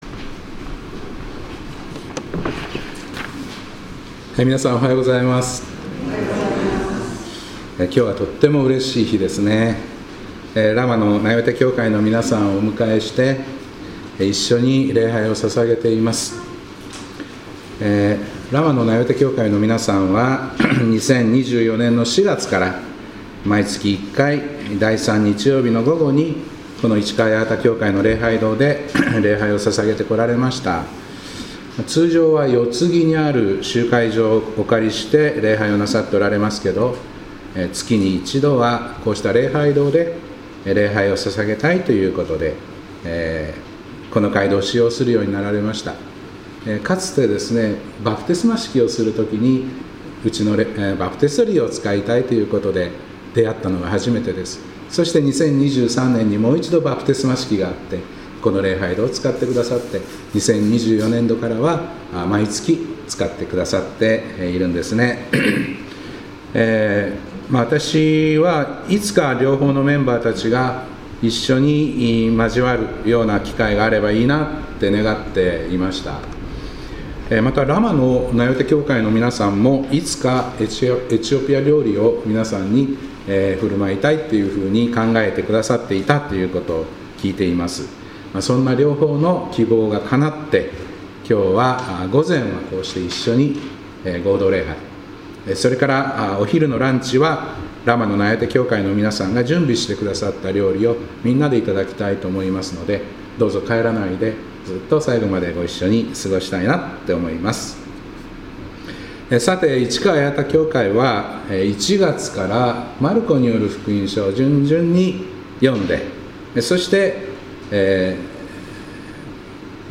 2026年2月15日礼拝「パンは向こう岸に」